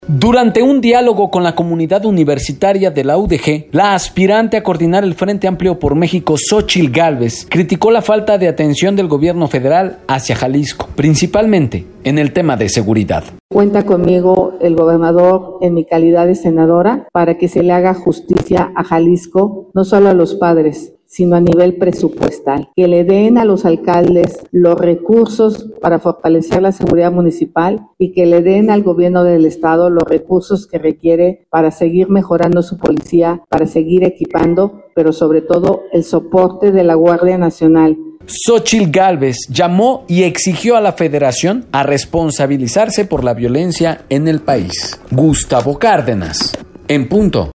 Durante un diálogo con la comunidad universitaria de la UdeG, la aspirante a coordinar el Frente Amplio por México, Xóchitl Gálvez criticó la falta de atención del Gobierno federal hacia Jalisco, principalmente en el tema de seguridad.